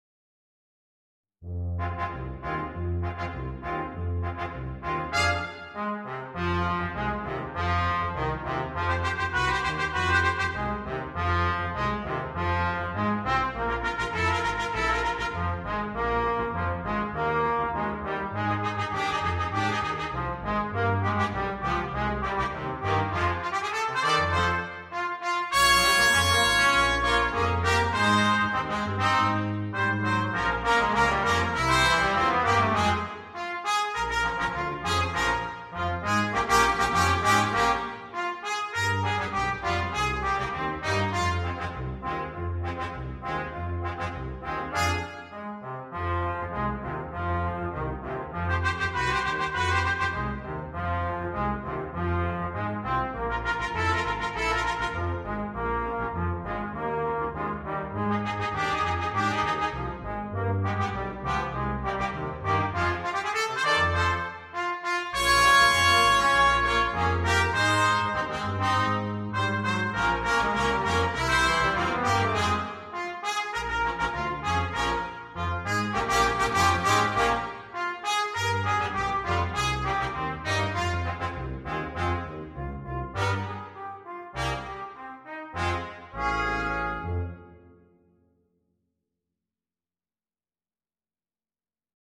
для брасс-бэнда
• состав: Trumpet in B 1, Trumpet in B 2,  Trombone, Tuba.